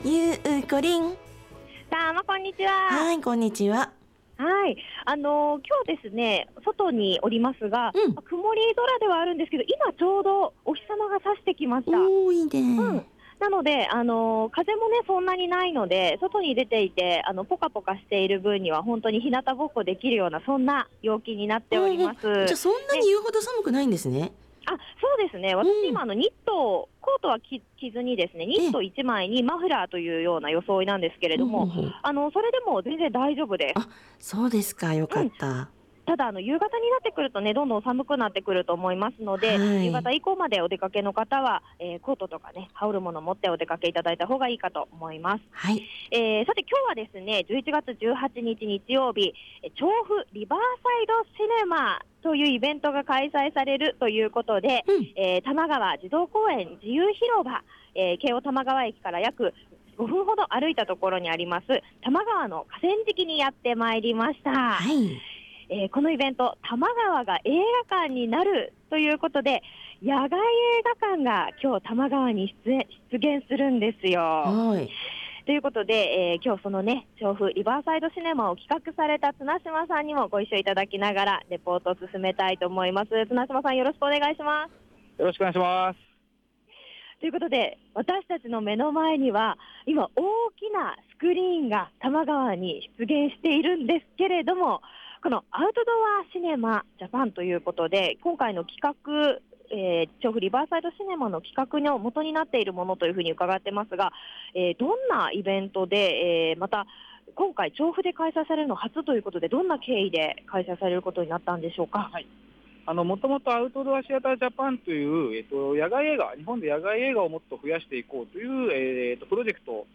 今日の街角レポートは、多摩川河川敷に行ってまいりました～！